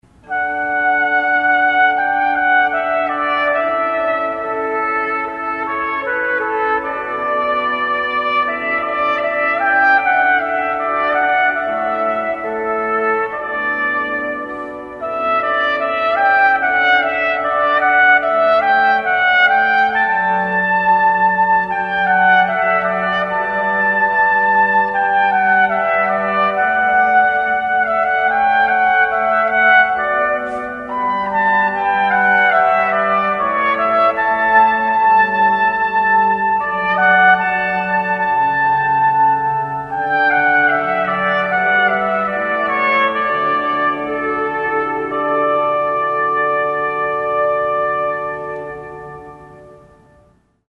meditative instrumental piece (mp3), or a vocal piece after the exchange of rings.  We often blend our warmest, sweetest sounds with a vocalist to enhance the music.